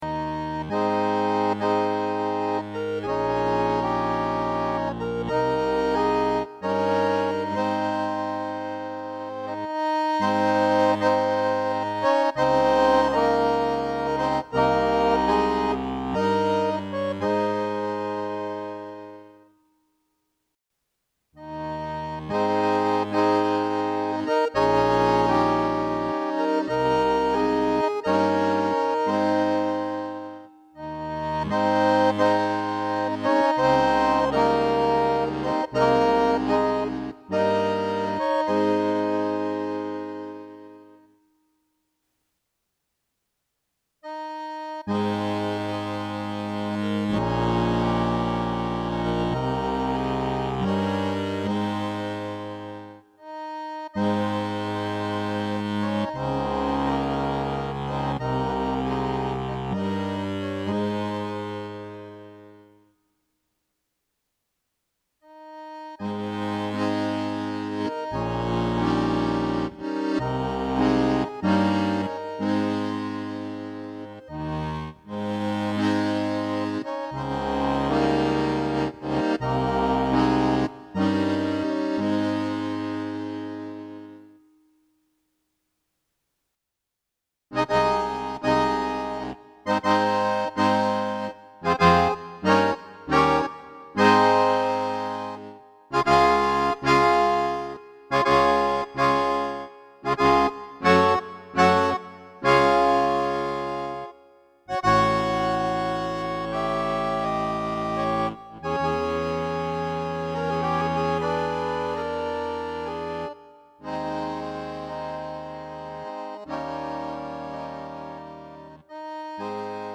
Wenn man diese Souveränität nicht hat, braucht man's nicht vorzuspielen . na gut, ein Beispiel (Konzertreife wird nicht angestrebt, Bassknick leider unvorteilhaft, sei's drum). Die ersten 8 Takte des Impromptus. 1. Diskant in etwa, wie's steht, Bass nur Basstöne - 2. dito, Bass zus. mit Begleitakkorden. 3. und 4. das selbe mit reduziertem Diskant zur Verdeutlichung - 5. noch ein paar Takte nächster Teil also da brauchst du kein MIII.